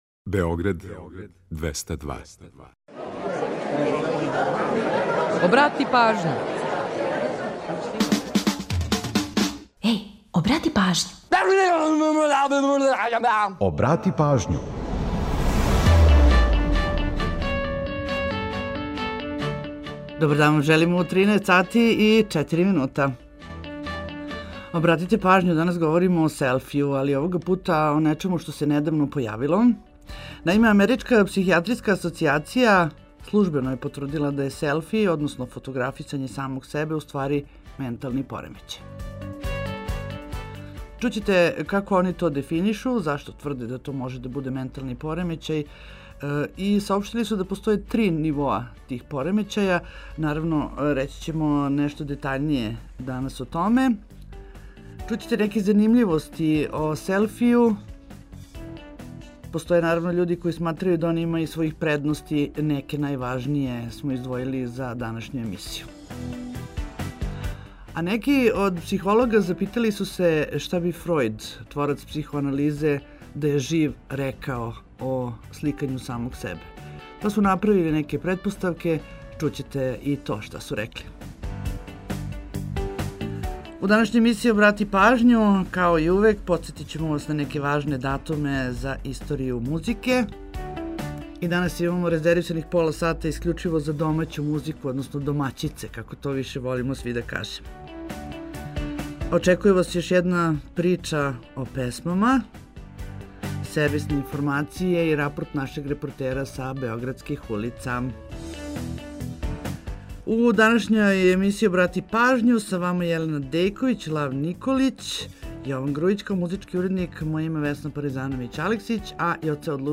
Неће изостати ни уобичајених пола сата резервисаних за домаћу музику, као ни прича о још једној песми.